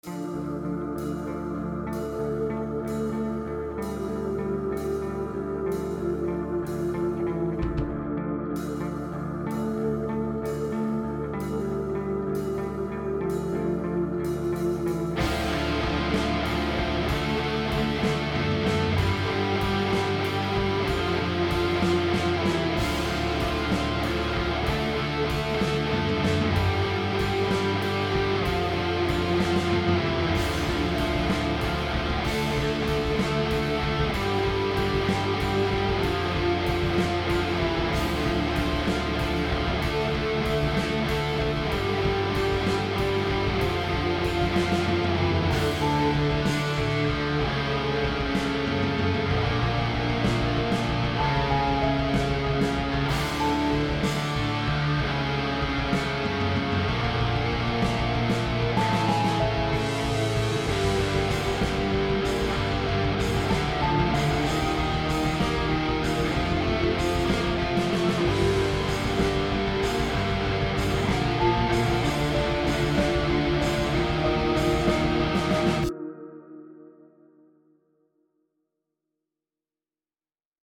Sh*t sound in headphones
I've been trying to record music for a long time, and have an issue that my guitar within VST sounds like sh*t. However, in speakers it sounds well.